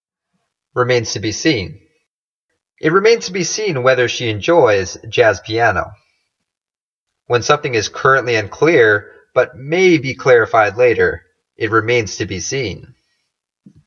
英語ネイティブによる発音は下記をクリックしてください。